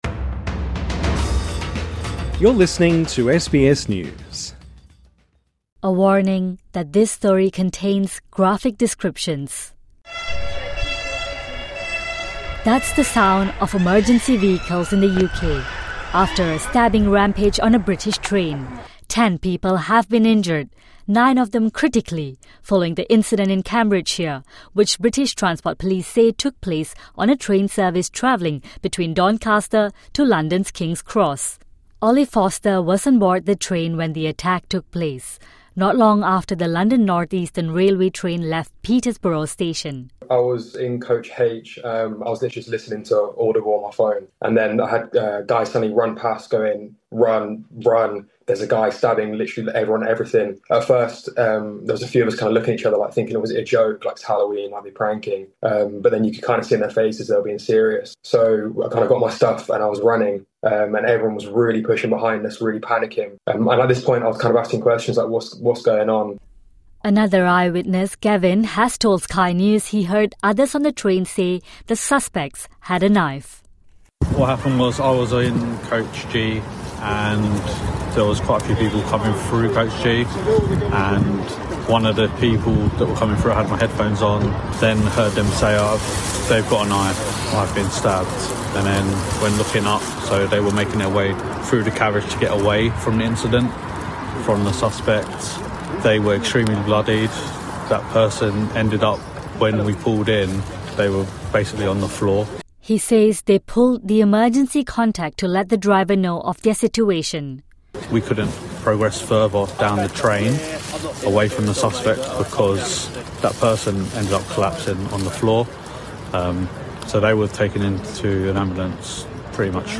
TRANSCRIPT: That's the sound of emergency vehicles in the U-K, after a stabbing rampage on a British train.